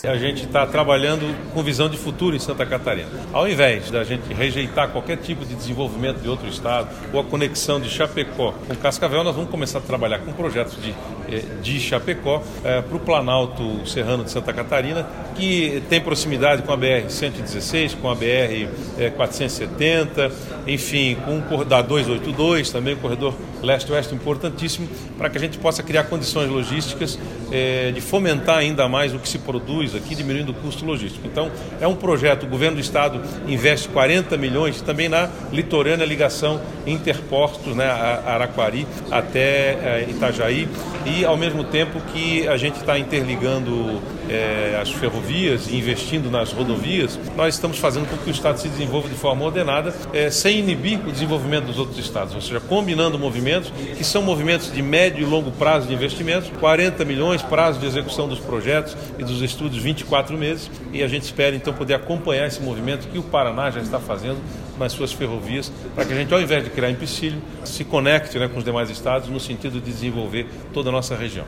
A assinatura ocorreu durante o encontro dos governadores do Codesul (Conselho de Desenvolvimento dos Estados do Sul), em Chapecó.
Governador Carlos Moisés explica sobre novas ferrovias; ouça